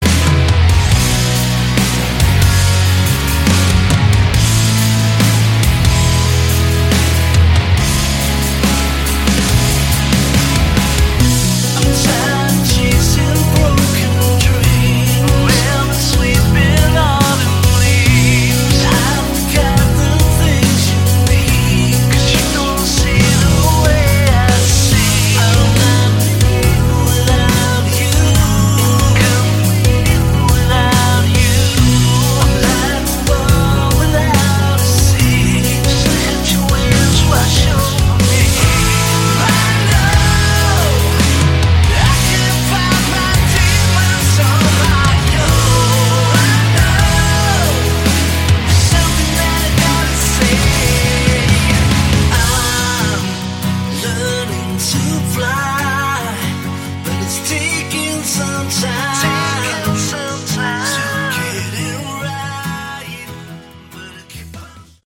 Category: Hard Rock
guitars
vocals
drums